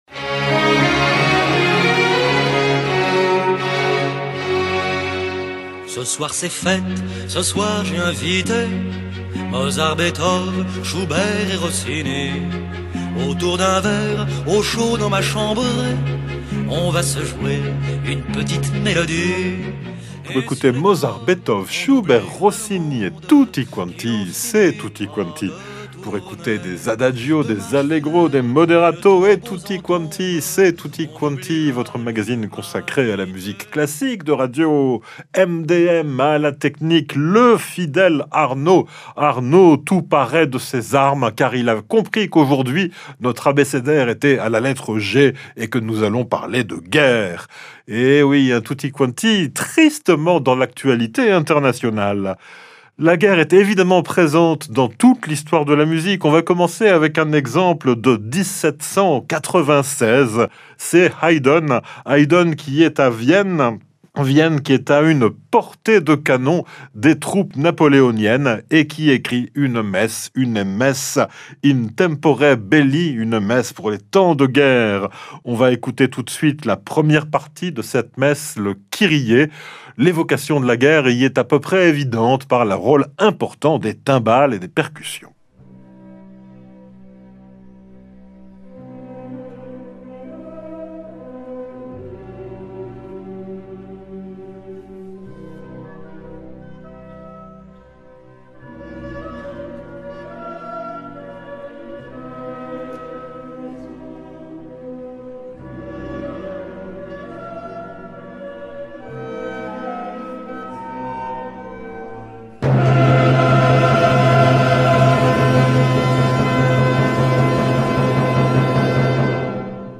Programmation musicale éclectique, multi-générationnelle, originale, parfois curieuse, alternative et/ou consensuelle, en tous les cas résolument à l’écart des grands réseaux commerciaux.